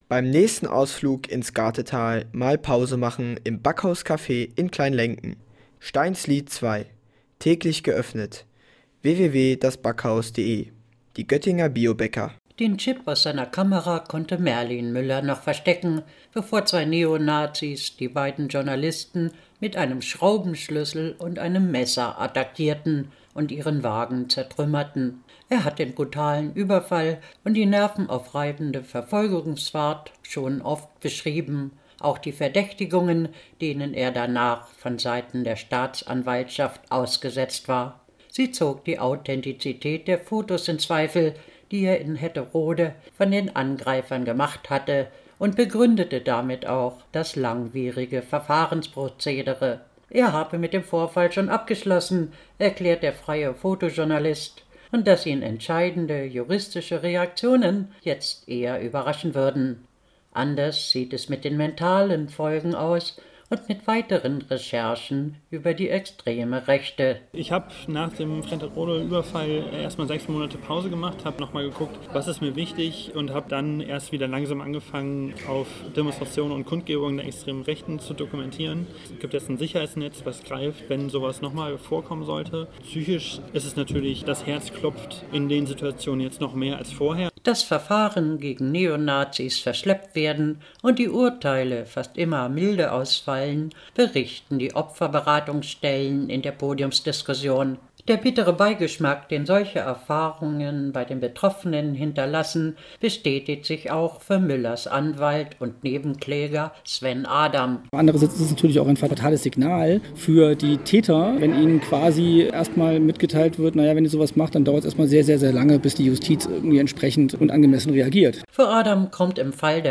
In Fretterode wurden sie von bewaffneten Neonazis angegriffen und schwer verletzt. Die Tatsache, dass die Anklage gegen die beiden Täter erst neun Monate später erfolgte und bis heute keine Gerichtsverhandlung stattfand, stand im Zentrum einer Podiumsdiskussion über „Rechte Gewalt und ihre Folgen“ im Alten Auditorium.